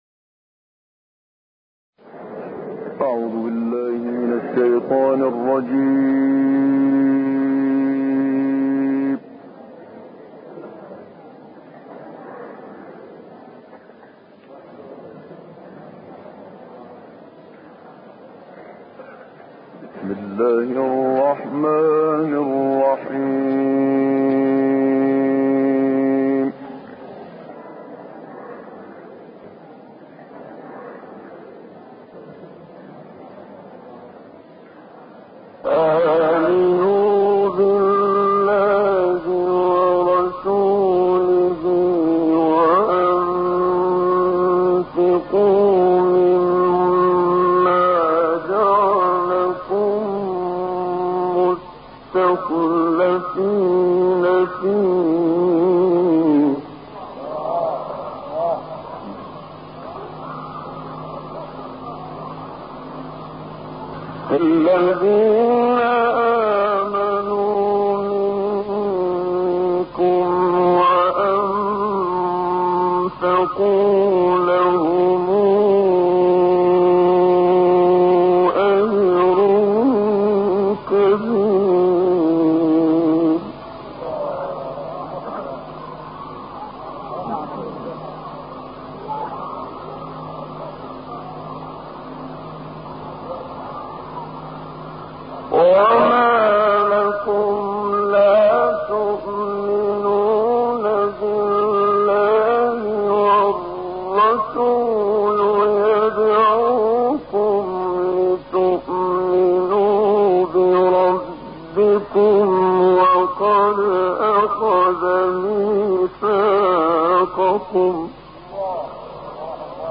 مصر - اسیوط